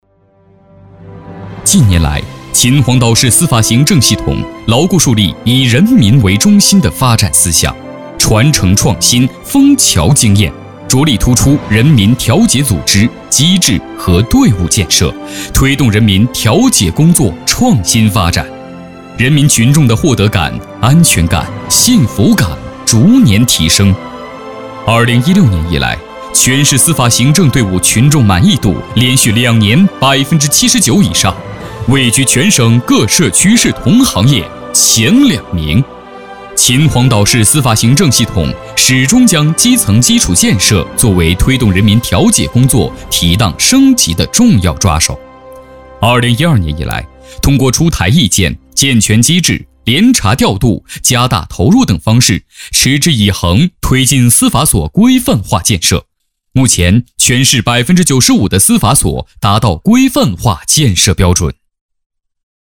大气浑厚 稳重磁性 激情力度 成熟厚重